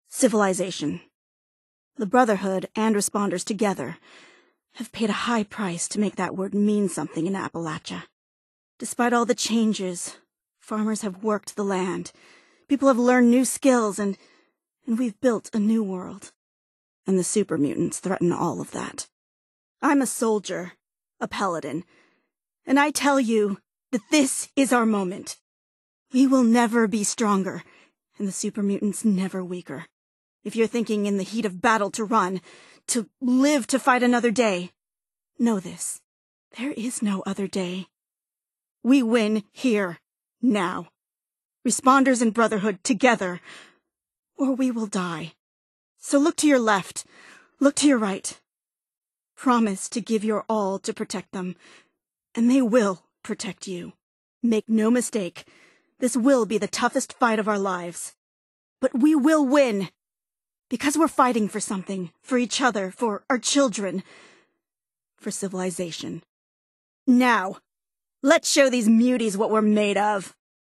Datei:Battle of Huntersville speech.ogg
Battle_of_Huntersville_speech.ogg